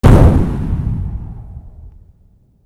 explosions.wav